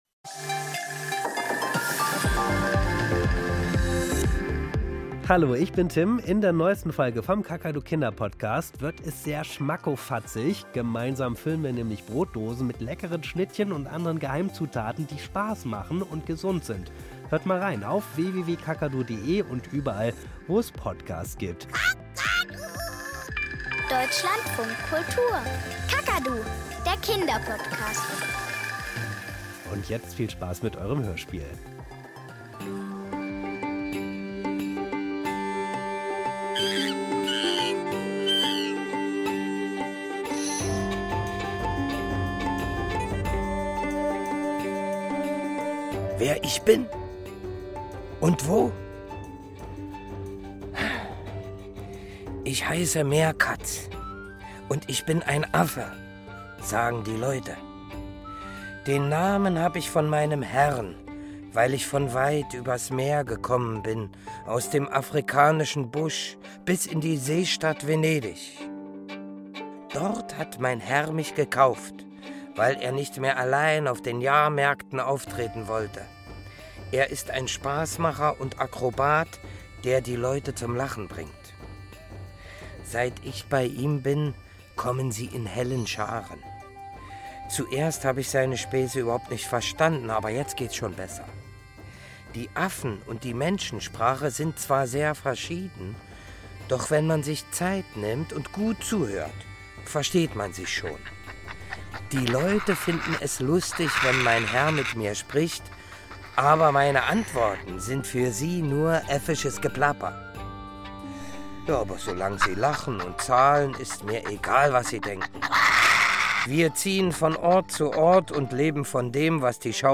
Kinderhörspiel - Eulenspiegel, der Seeräuber